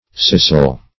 scissil - definition of scissil - synonyms, pronunciation, spelling from Free Dictionary Search Result for " scissil" : The Collaborative International Dictionary of English v.0.48: Scissil \Scis"sil\, n. See Scissel .